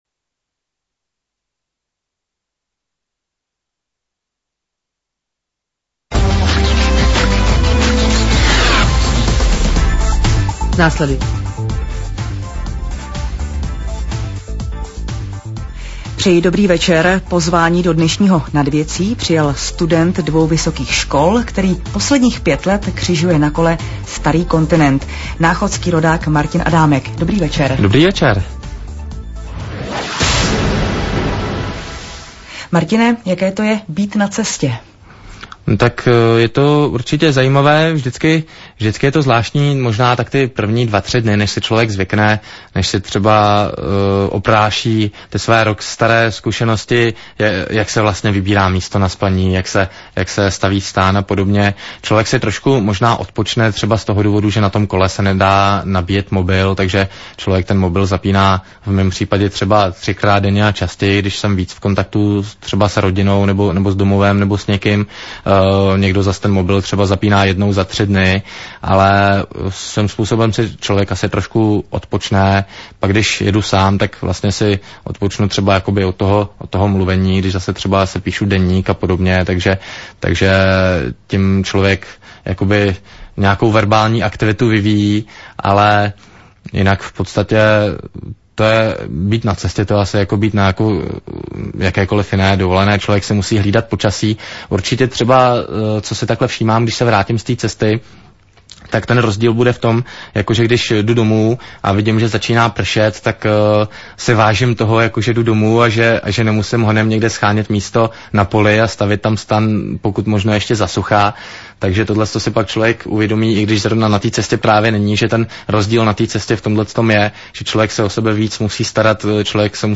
Živý studiový rozhovor o všech cestách (!!)